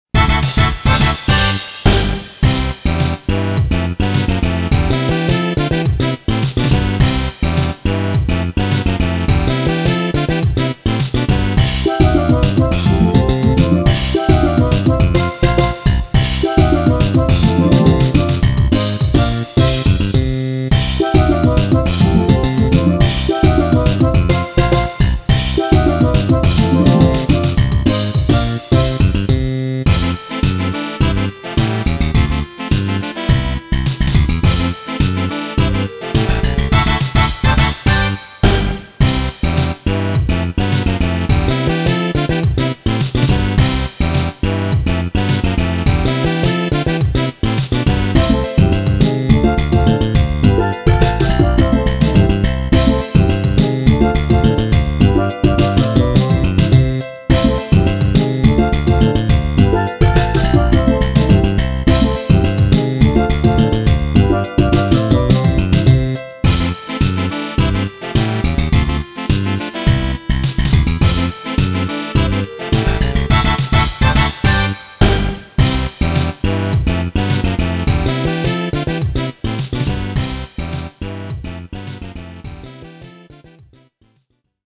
この曲はドラムアレンジ。
これらの曲は、全てドリームキャストを使って作りました。